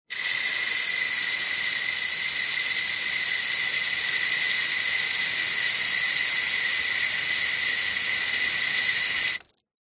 Звуки сада
Внешний водопроводный кран, включение, вода течет на камни, выключение, слабое давление, шипение